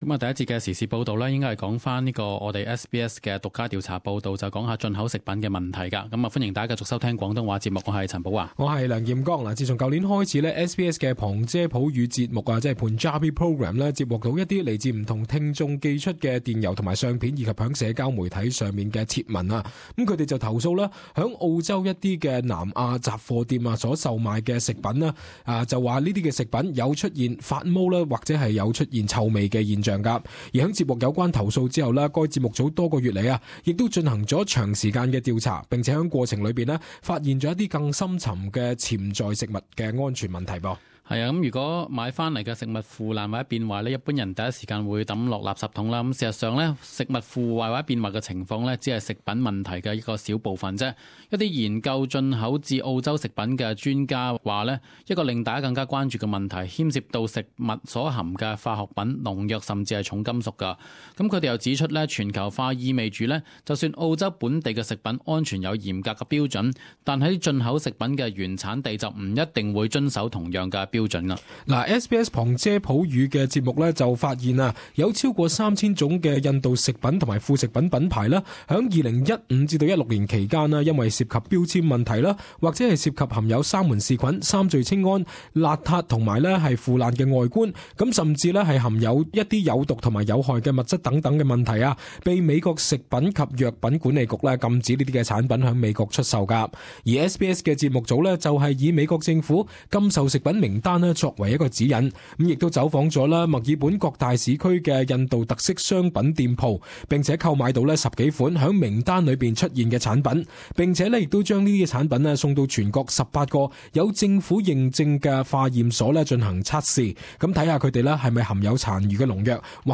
【時事報導】SBS獨家「危險食品」調查